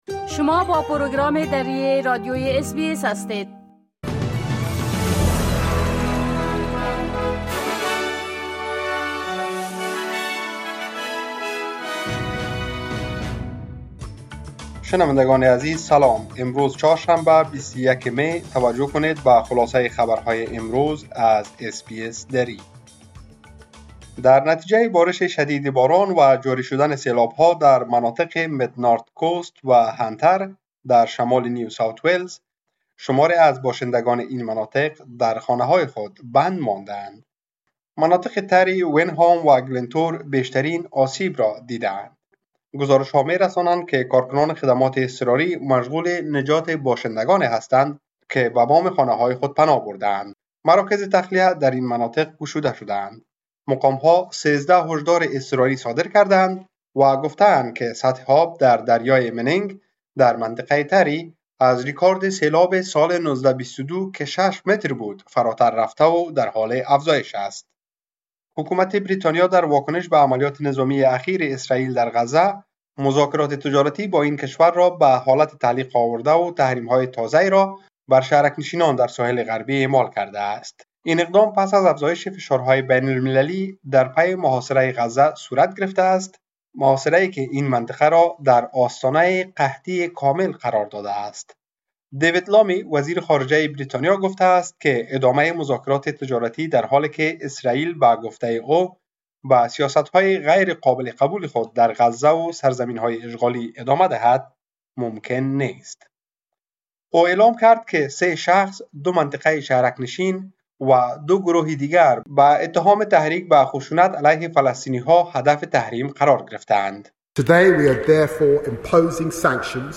خلاصه مهمترين اخبار روز از بخش درى راديوى اس بى اس | ۲۱ می ۲۰۲۵